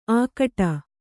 ♪ ākaṭa